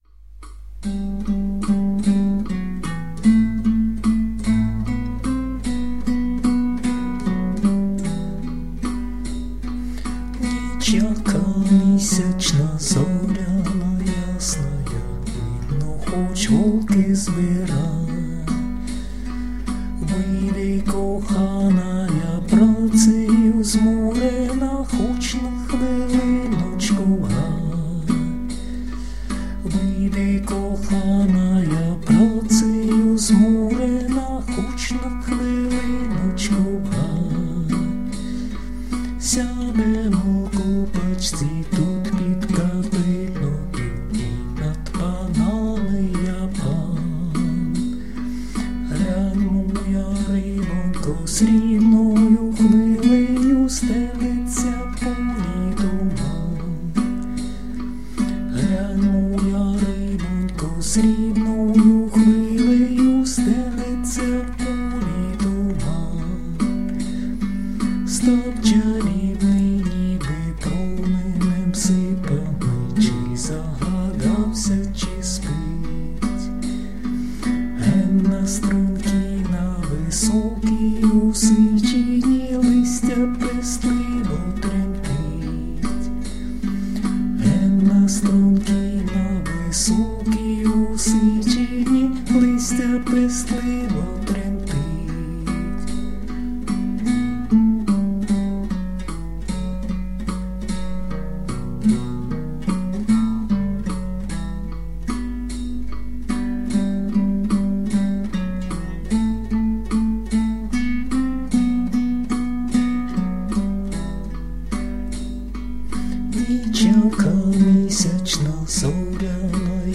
../icons/nochsvet.jpg   Українська народна пiсня